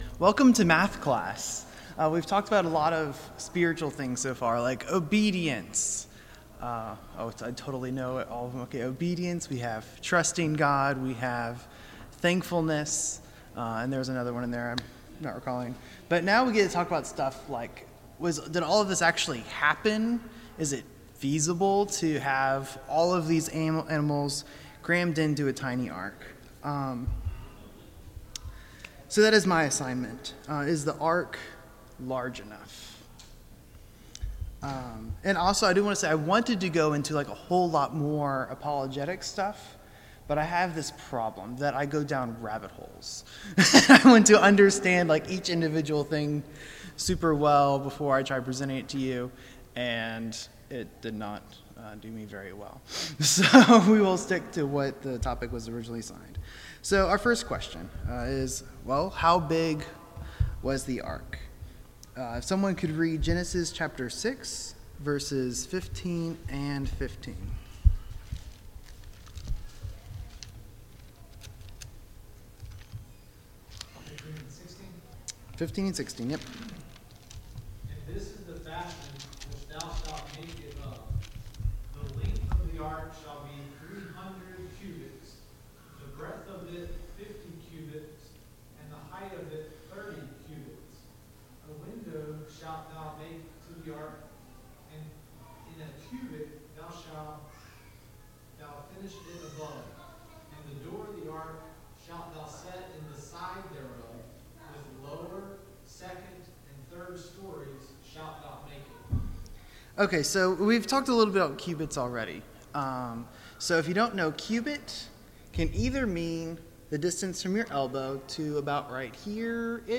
Service Type: VBS Adult Class